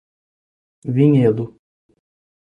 Pronunciado como (IPA)
/vĩˈɲe.du/